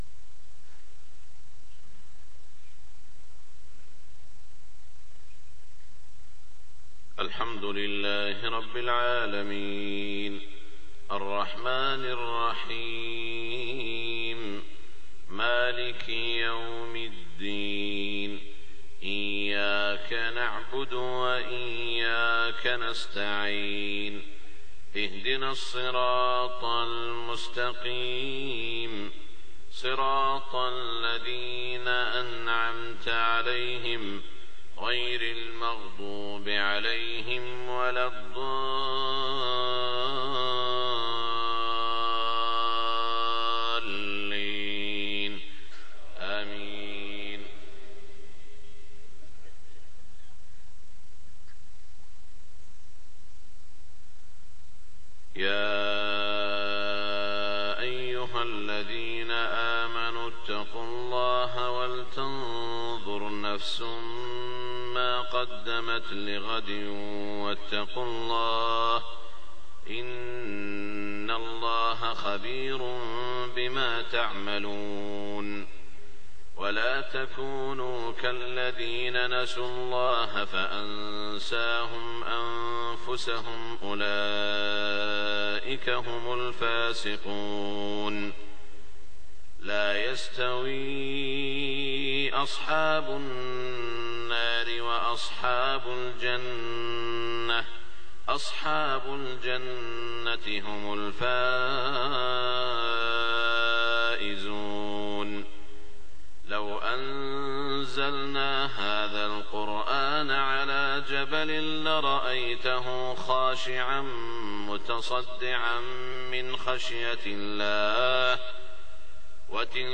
صلاة الفجر 19 ذو القعدة 1427هـ من سورتي الحشر و النازعات > 1427 🕋 > الفروض - تلاوات الحرمين